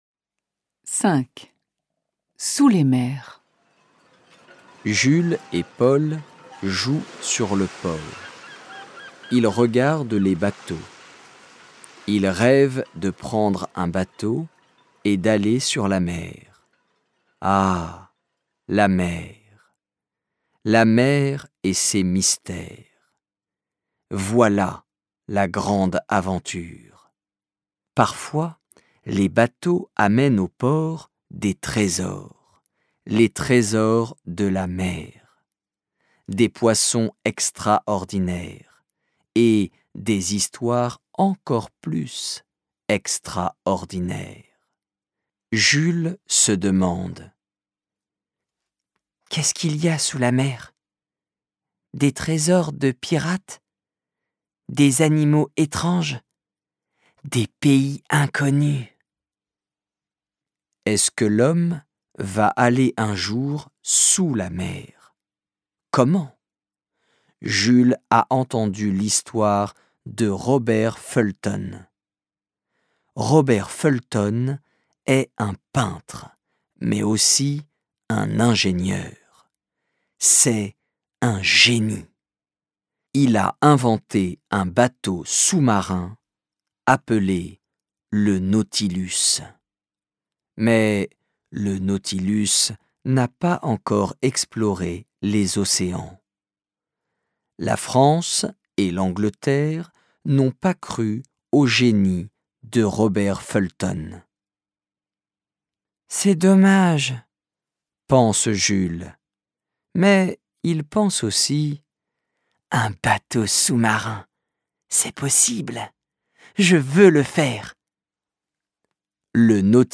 Lecture simplifiée: Les romans de Jules Verne